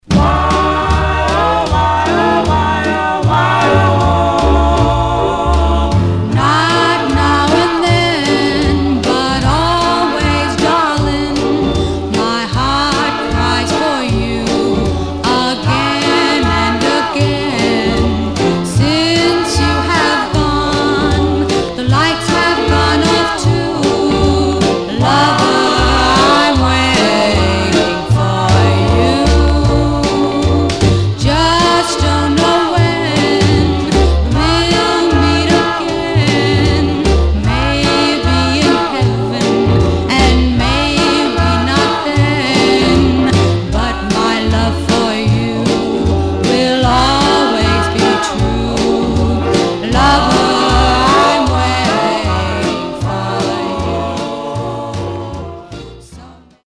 Stereo/mono Mono
Male Black Group Sentimentals